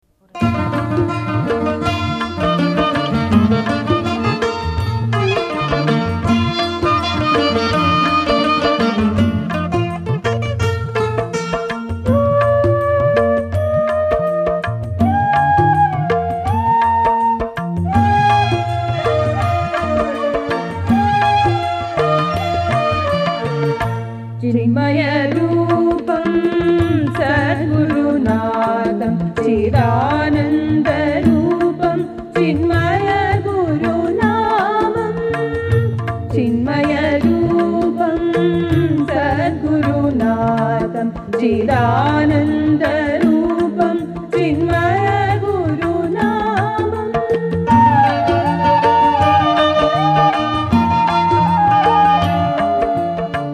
Bhajans